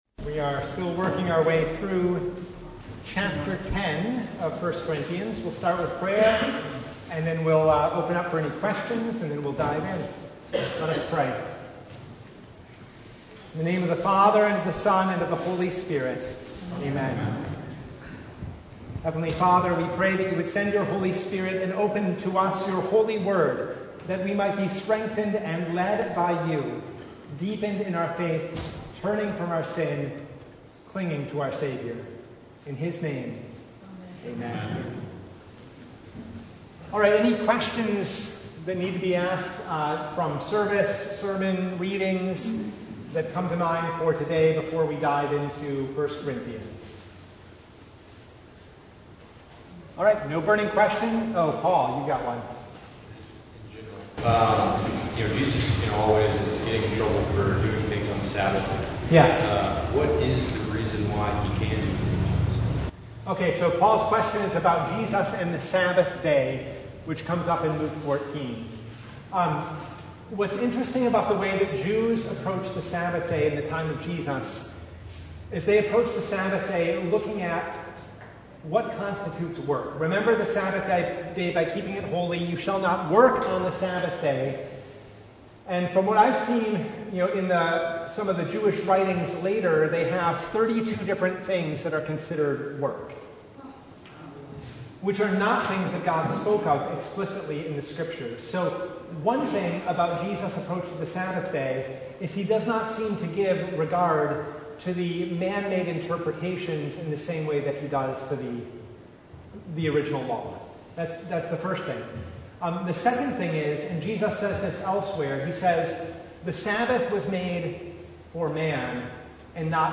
1 Corinthians 10:14-22 Service Type: Bible Hour Topics: Bible Study « From Pride to Humility The Fifth Sunday in Martyrs’ Tide